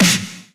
snare3.mp3